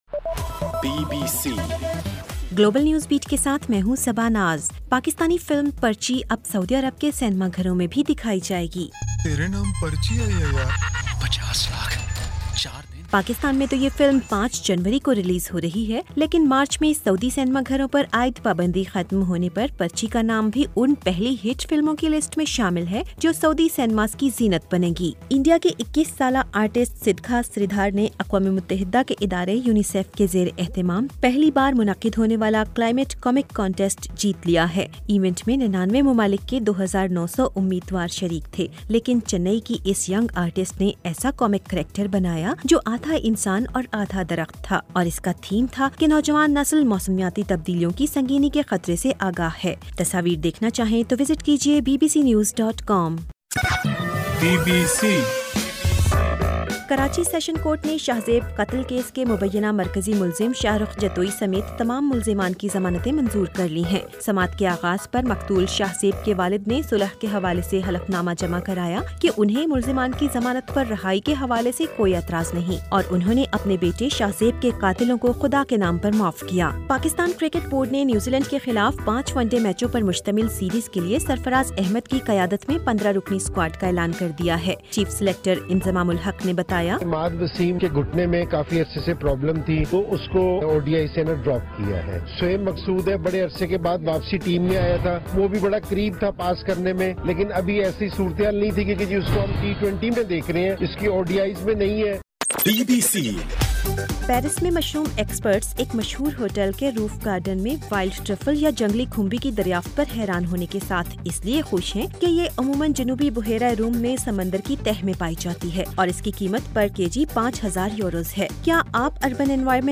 گلوبل نیوز بیٹ بُلیٹن اُردو زبان میں رات 8 بجے سے صبح 1 بجے تک ہر گھنٹےکے بعد اپنا اور آواز ایفایم ریڈیو سٹیشن کے علاوہ ٹوئٹر، فیس بُک اور آڈیو بوم پر ضرور سنیے